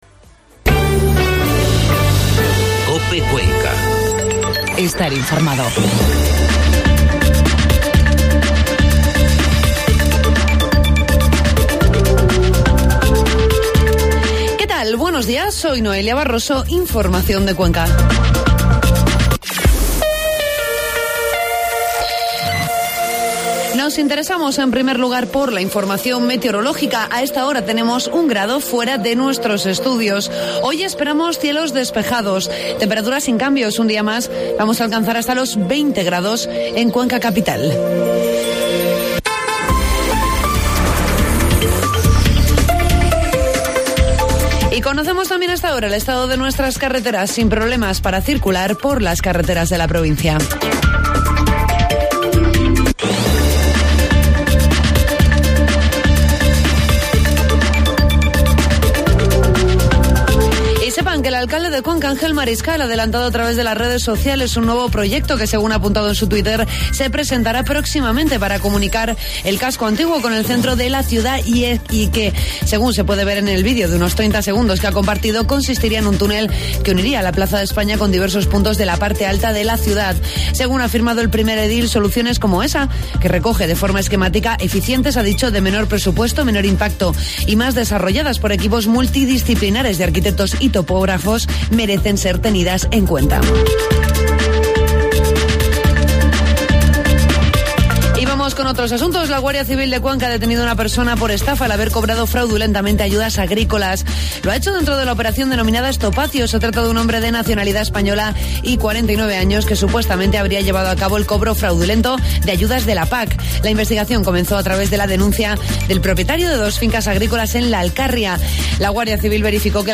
Informativo matinal COPE Cuenca 21 de noviembre